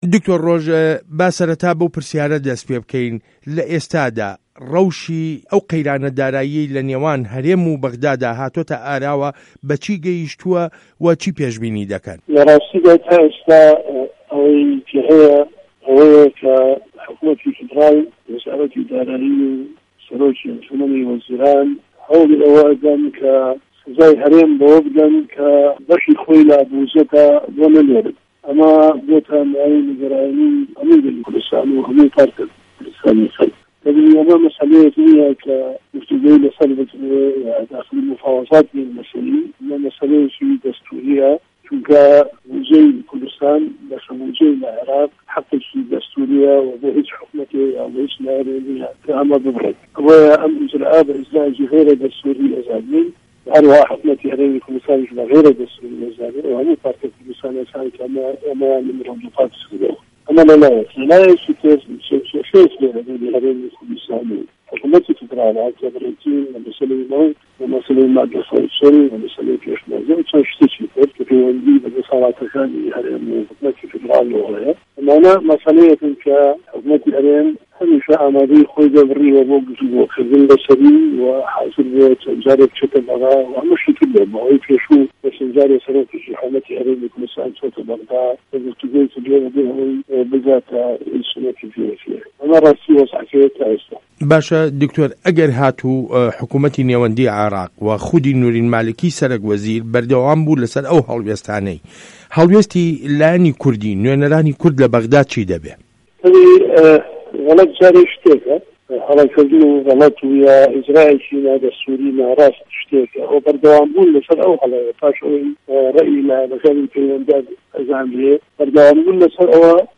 وتووێژ له‌گه‌ڵ دکتۆر ڕۆژ نوری شاوێس